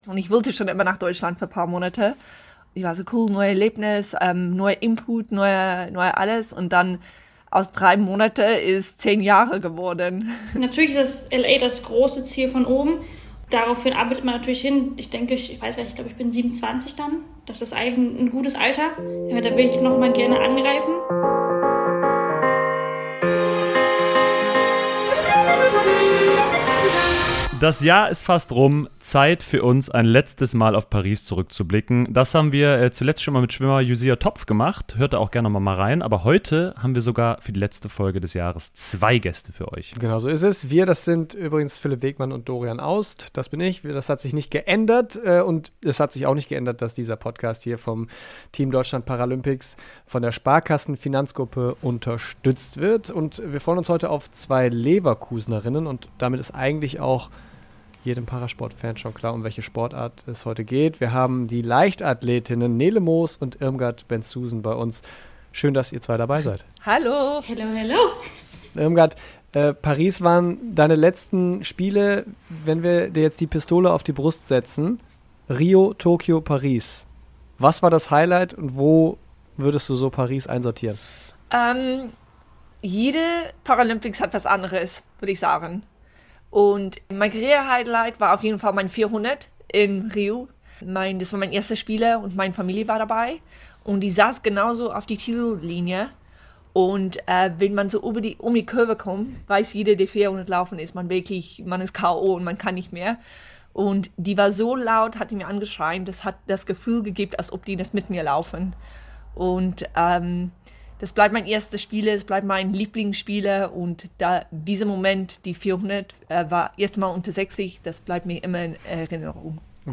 Im Team Deutschland Paralympics Podcast sprechen die beiden über Paris, Zukunftspläne wie LA 2028 und Südafrika und ihre (Trainings-)Freundschaft.